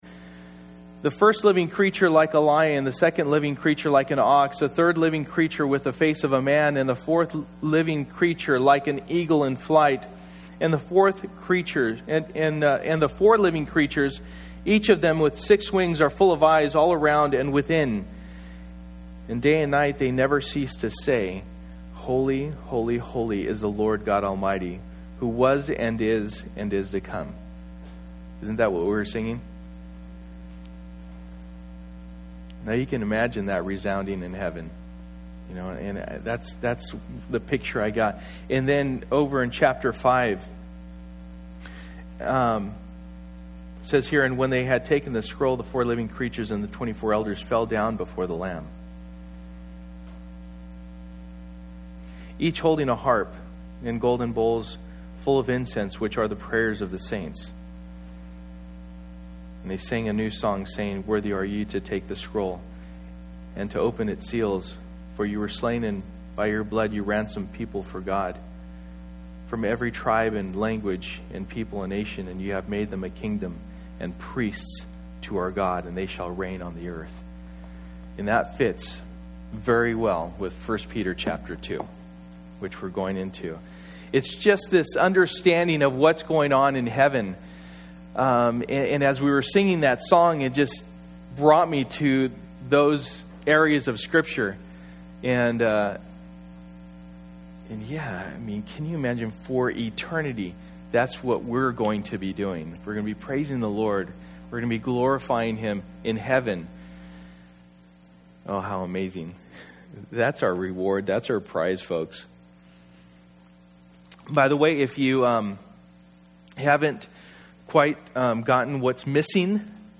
Passage: 1 Peter 2:1-10 Service: Sunday Morning